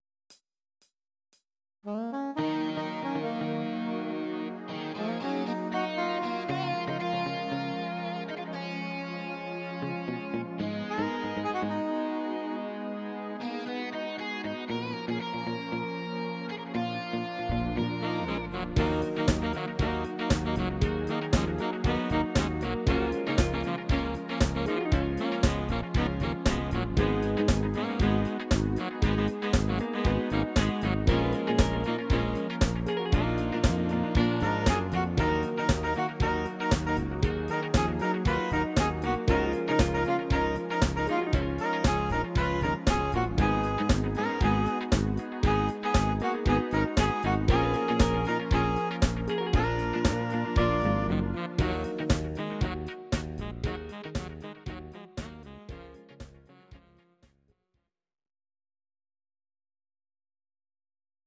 Audio Recordings based on Midi-files
Pop, Ital/French/Span, 2000s